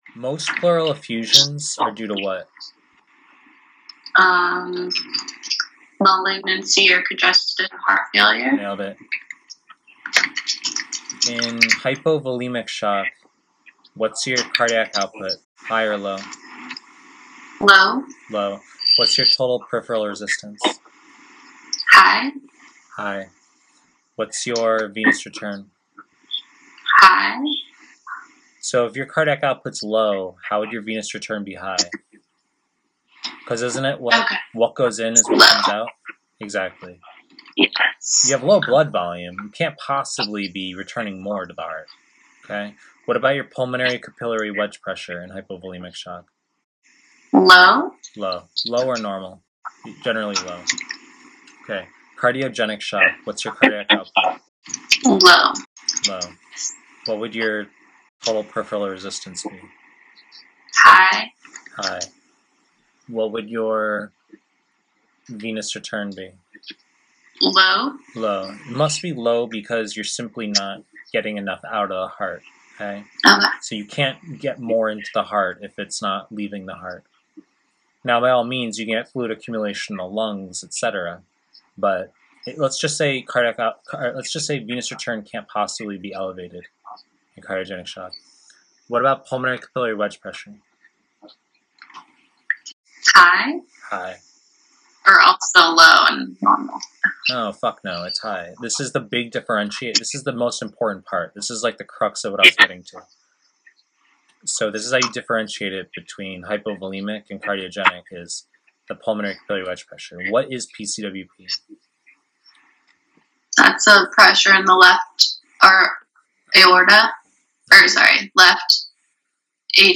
Pre-recorded lectures
(sorry about the slightly shyte sound quality on this one)